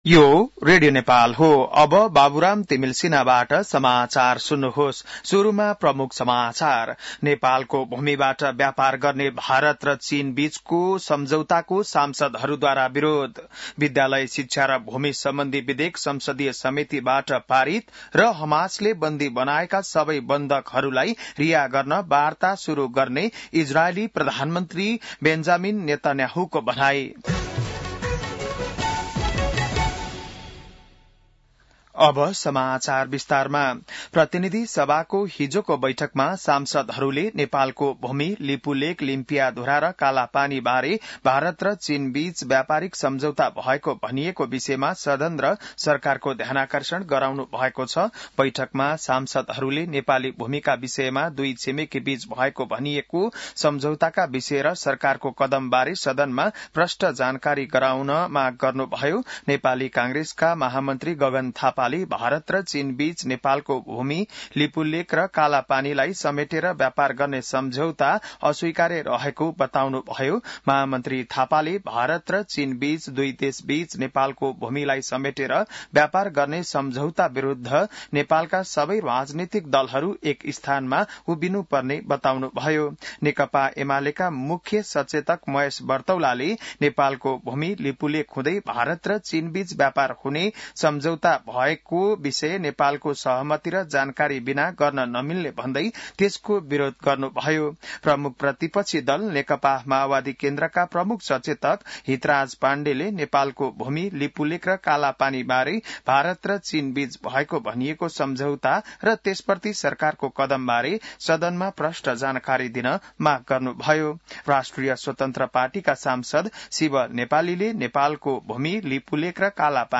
बिहान ९ बजेको नेपाली समाचार : ६ भदौ , २०८२